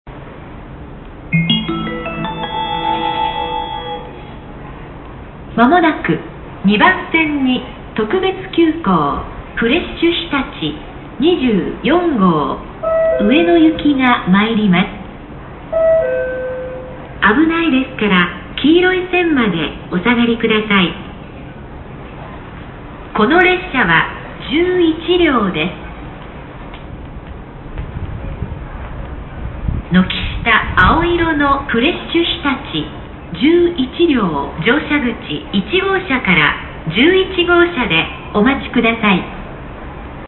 接近放送特急フレッシュひたち24号上野行き11両
特急フレッシュひたち24号の接近放送です。
「○番線」の部分が改良されているのが特徴です。